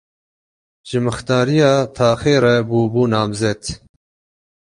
Pronunciado como (IPA)
/nɑːmˈzɛt/